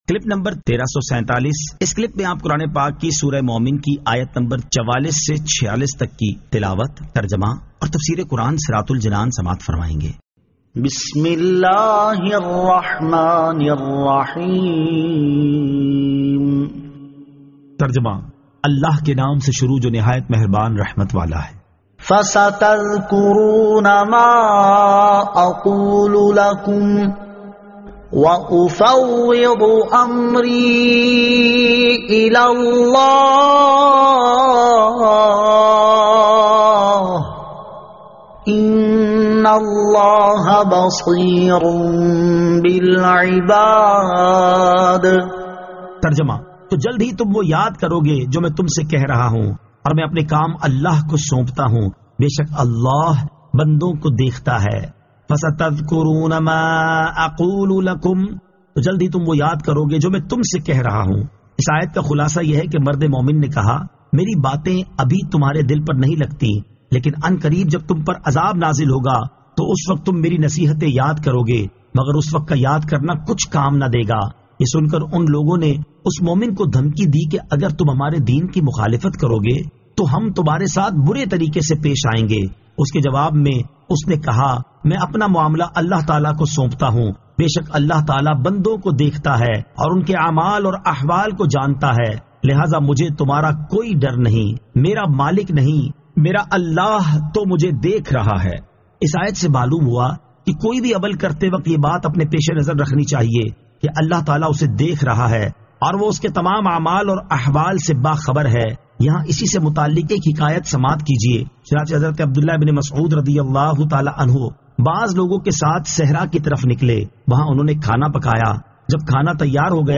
Surah Al-Mu'min 44 To 46 Tilawat , Tarjama , Tafseer